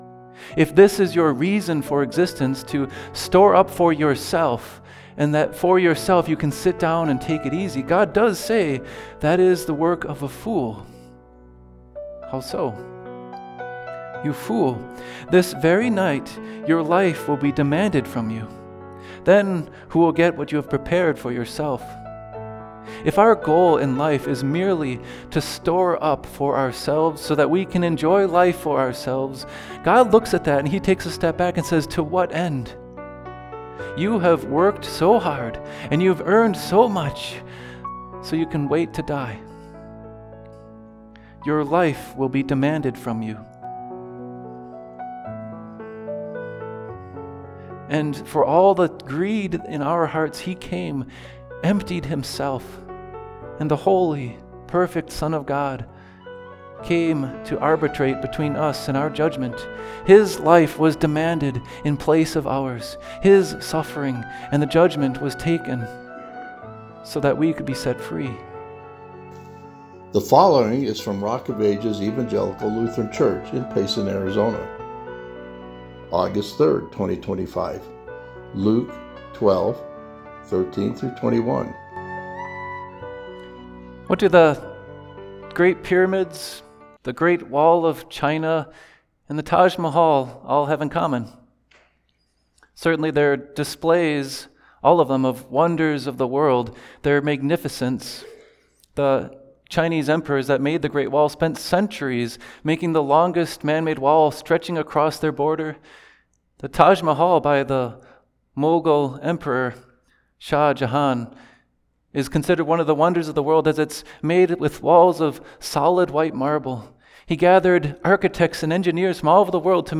Luke 12:13-21 ● 2025-07-06 ● Listen to sermon audio